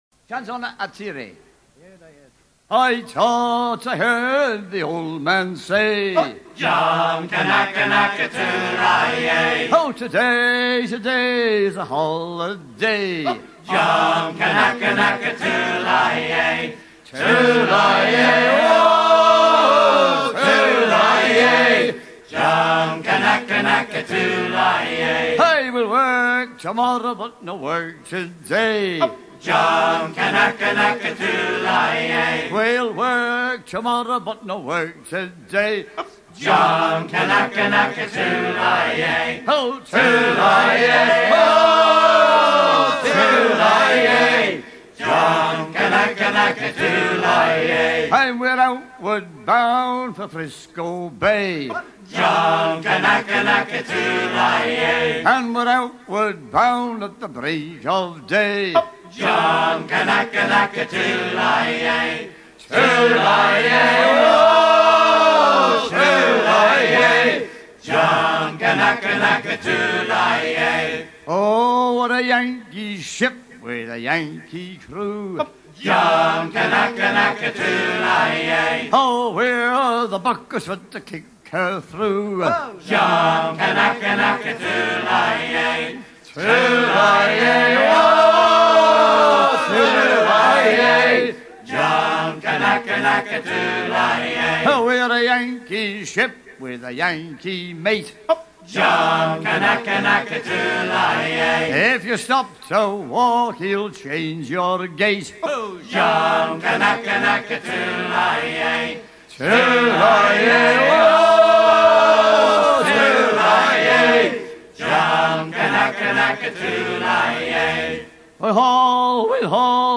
gestuel : à hisser à grands coups
circonstance : maritimes
Pièce musicale éditée